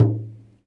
萨满教鼓（buben） " Buben2
描述：一个萨满鼓（buben）的样本，大约15年前在Kurgan镇的西西伯利亚录制。
Tag: 萨满鼓 萨满-簿本 撒满鼓 撒满-簿本